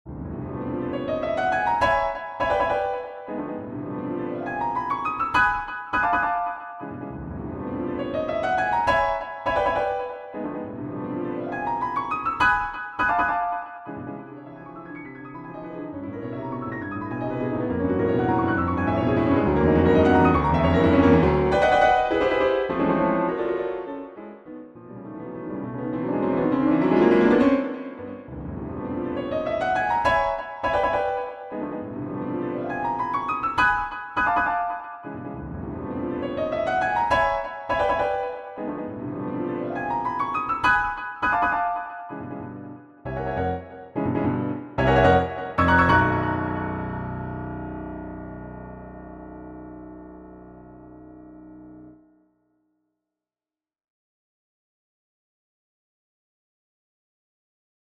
Persichetti Exercise 2 - 15 for Piano Four Hands
I chose to use C major and C# Dorian as my composite tw...